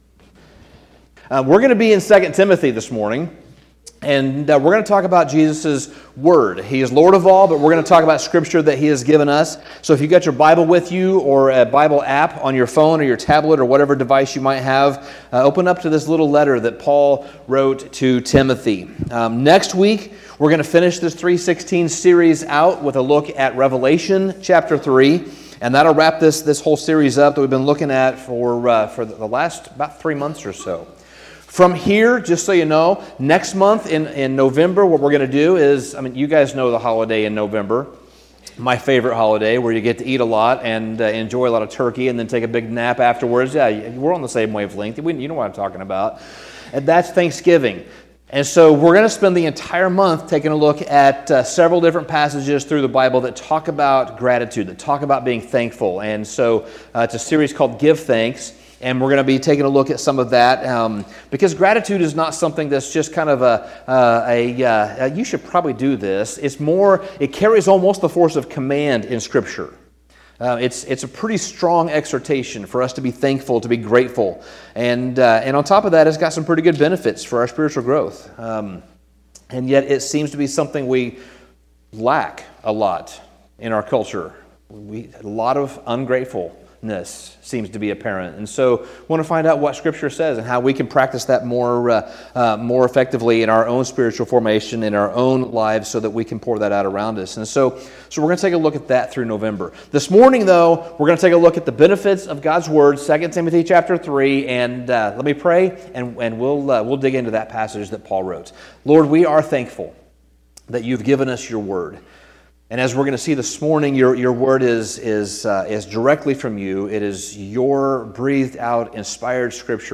Sermon Summary Paul wrote two letters to his young protégé Timothy.